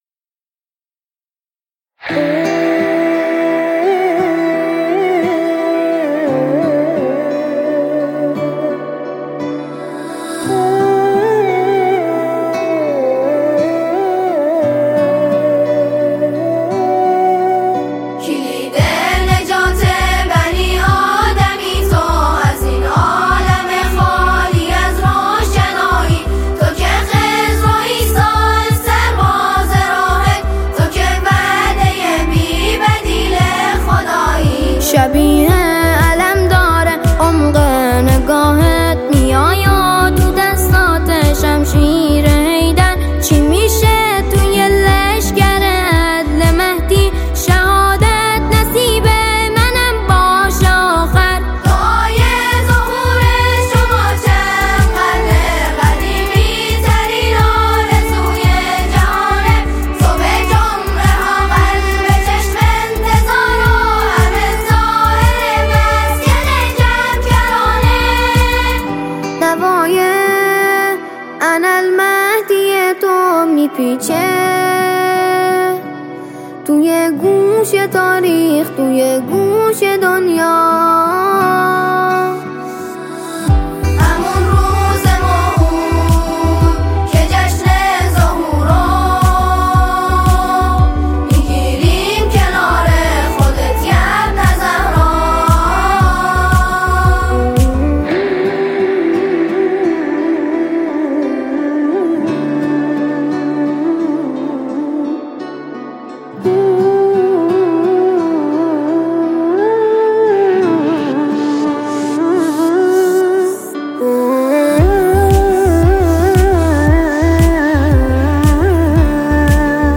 طنین صدای معصومانه‌ی کودکان در صحن و سرای مسجد مقدس جمکران
سرود مذهبی